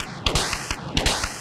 Index of /musicradar/rhythmic-inspiration-samples/170bpm